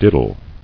[did·dle]